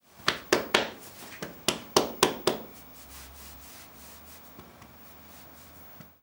Abrazo, golpes en la espalda
palmada
Sonidos: Acciones humanas